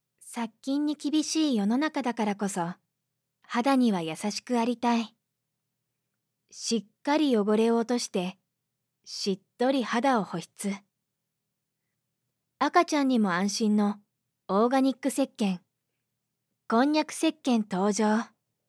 I have a soft, soothing voice and can provide narration for corporate profile video, commercials, readings, etc., according to your image.
I also specialize in naturalistic narration.
– Narration –
Whisper